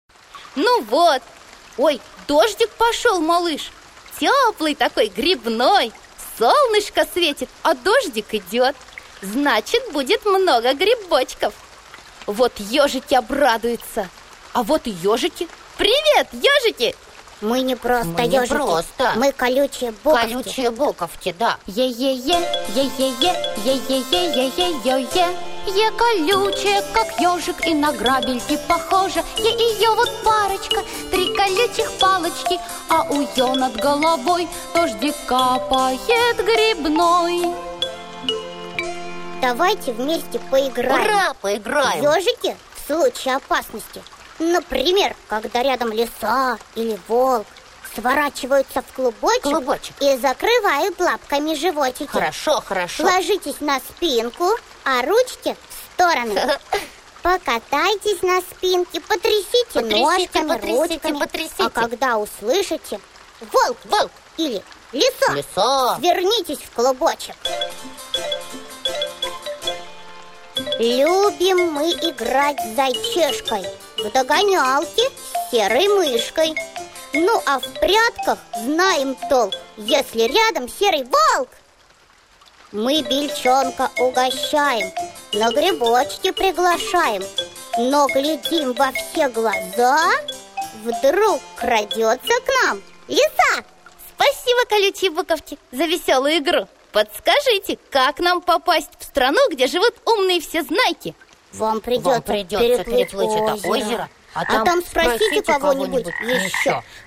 Аудиосказки: 09 - Буквы Е и Ё